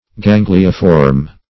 Search Result for " ganglioform" : The Collaborative International Dictionary of English v.0.48: Gangliform \Gan"gli*form`\, Ganglioform \Gan"gli*o*form`\, a. [Ganglion + -form.]